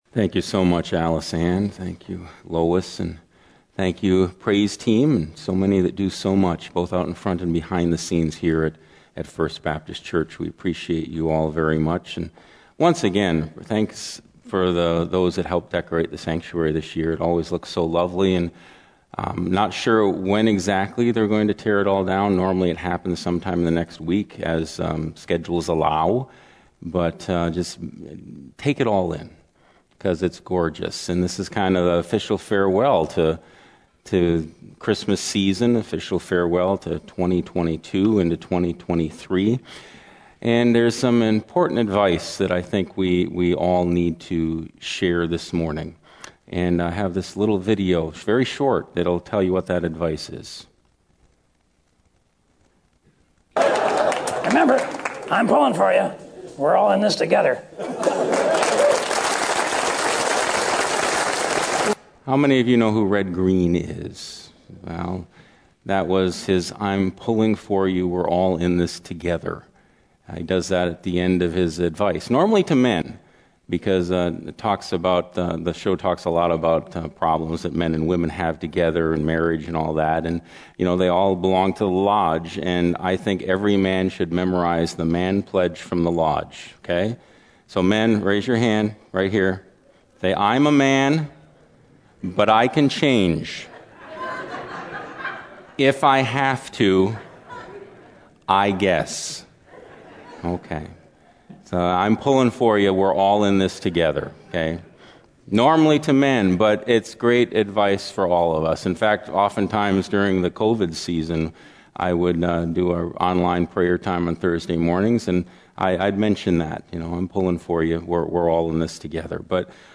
First Baptist Church Sermons